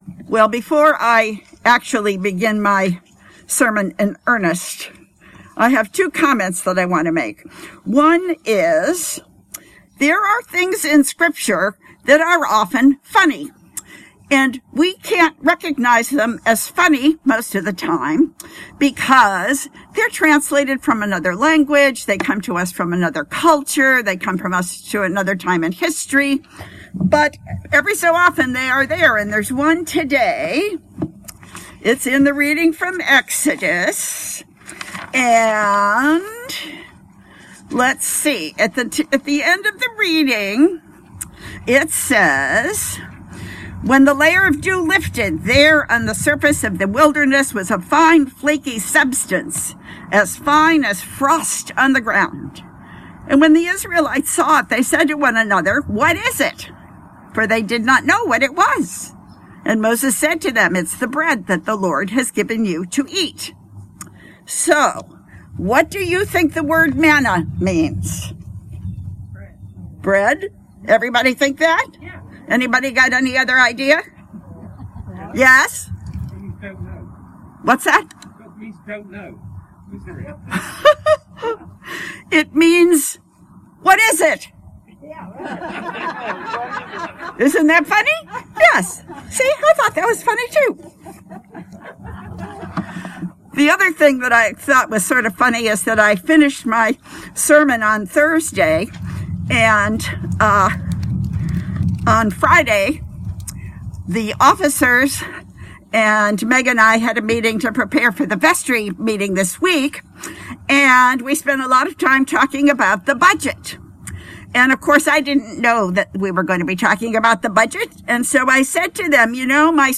Sermons at Christ Episcopal Church, Rockville, MD
When Is Enough Enough? Sermon Download audio file